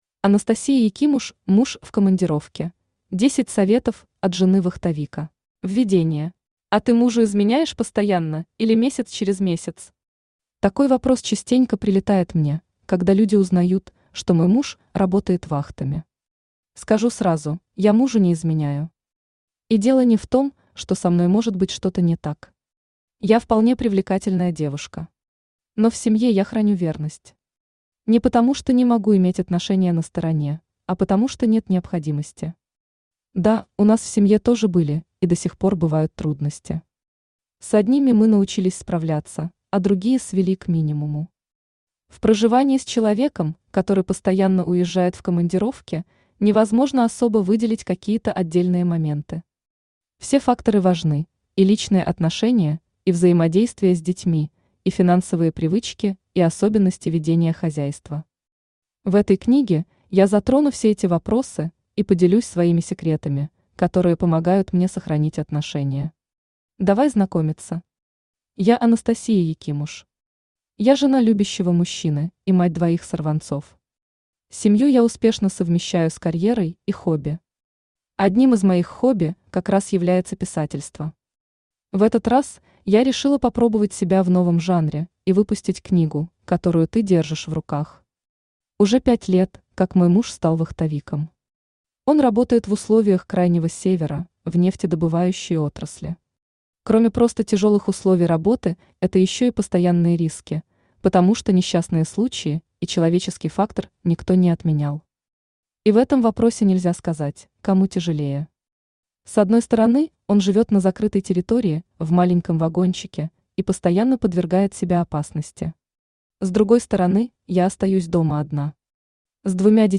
Aудиокнига Муж в командировке. 10 советов от жены вахтовика Автор Анастасия Якимуш Читает аудиокнигу Авточтец ЛитРес.